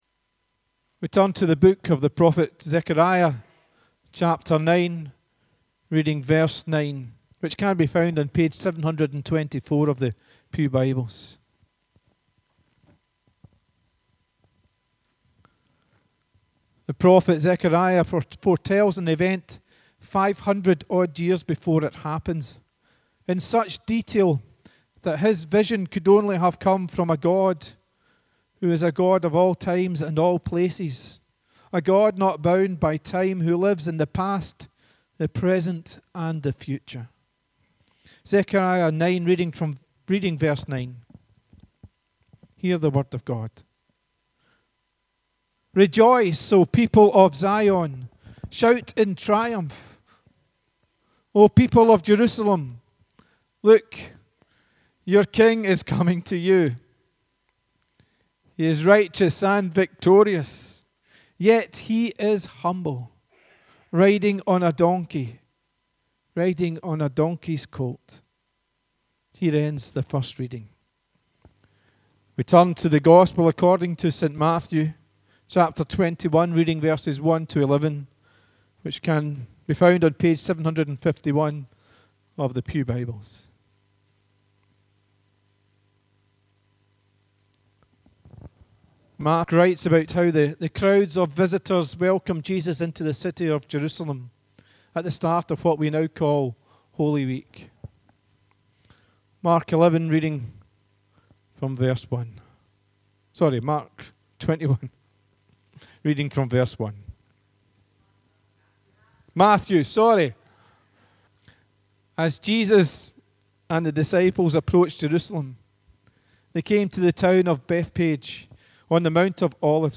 The readings prior to the sermon are Zechariah 9: 9 and Matthew 21: 1-11